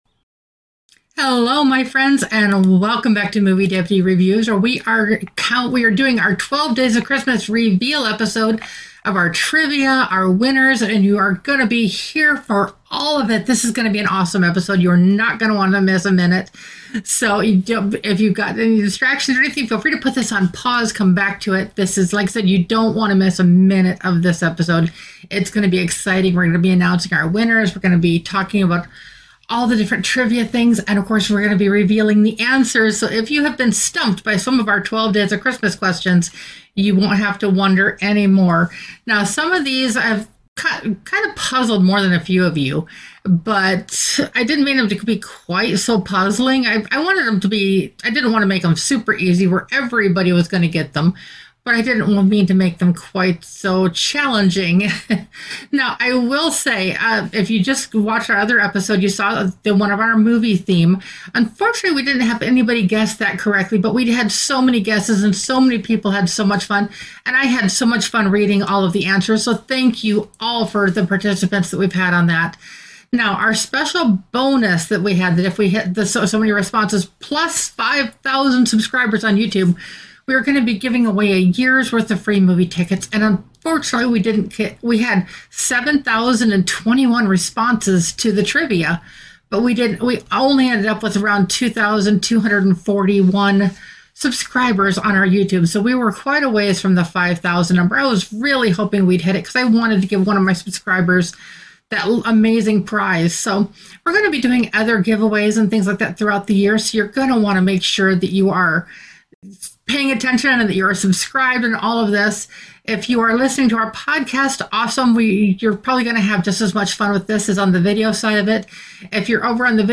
The audio has been corrected.